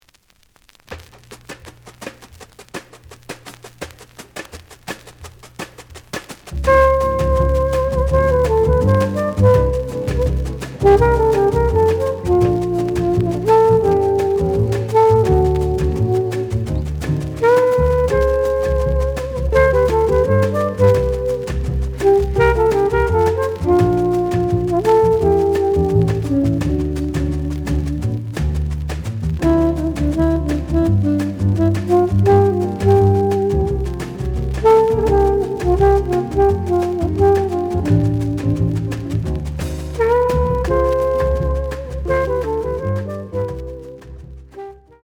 The audio sample is recorded from the actual item.
●Format: 7 inch
●Genre: Cool Jazz